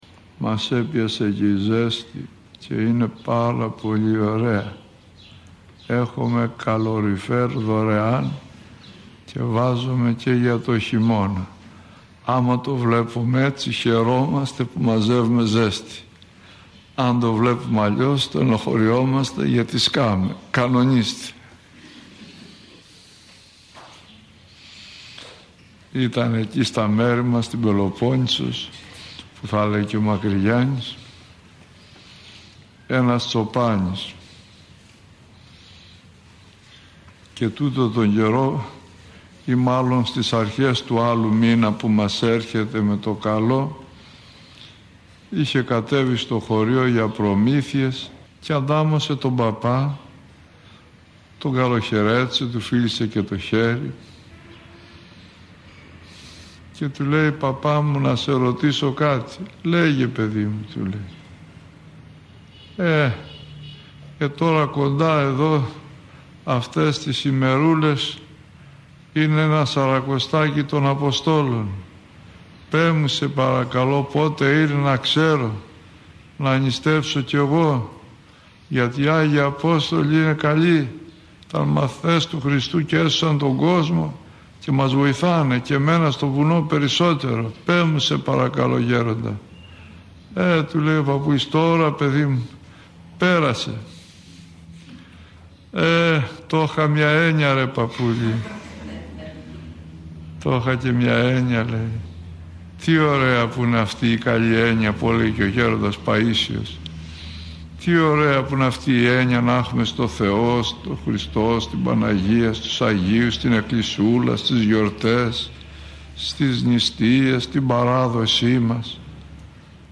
Άγιοι που εορτάζουν από 26 έως 30 Ιουνίου – ηχογραφημένη ομιλία